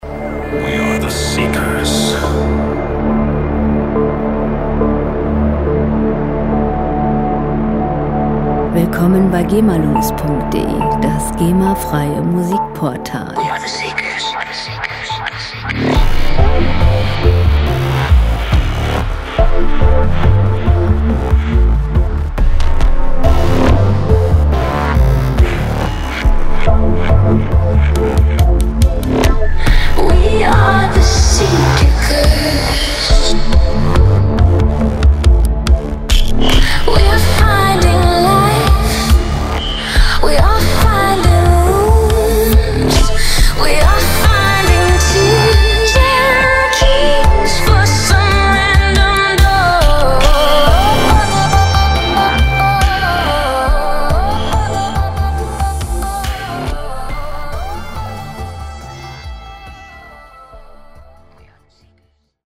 • Psystep